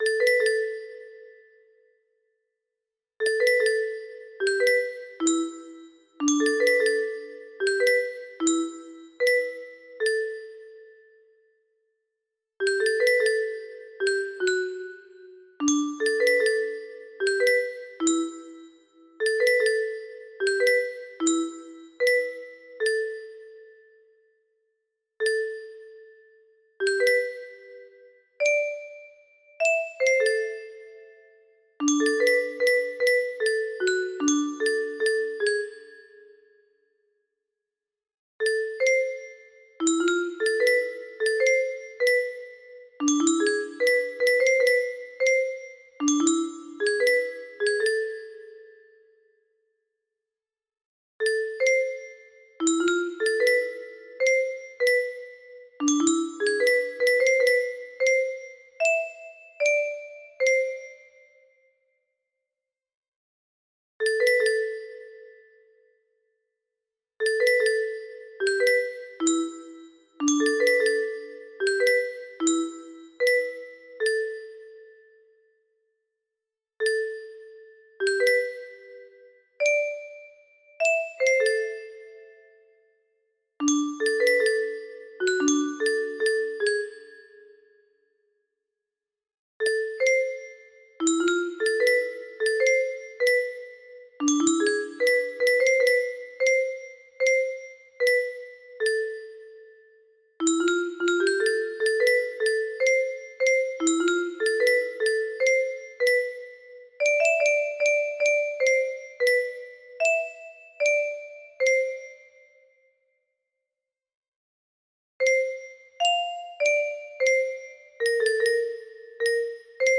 Specially adapted for 30 notes